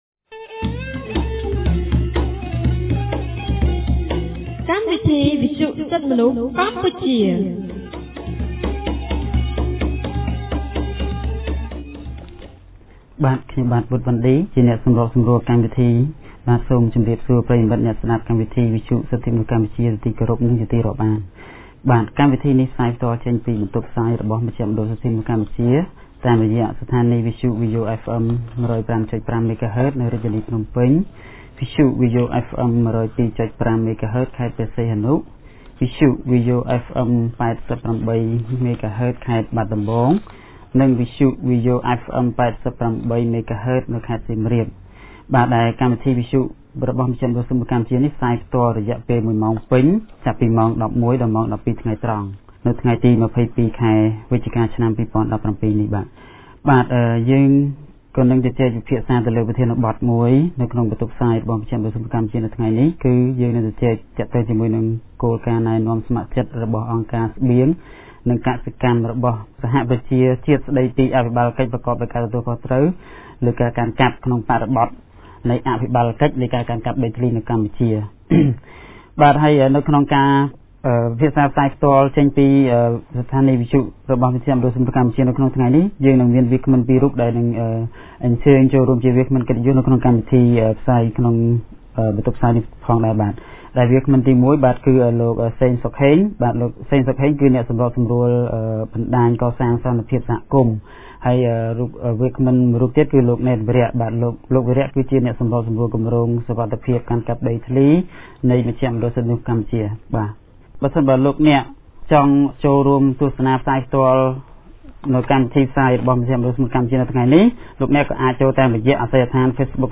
On Wednesday 22 November 2017, CCHR’s radio program held a talk show on topic “ The Voluntary Guidelines on the Responsible Governance of Tenure ("VGGT")’’.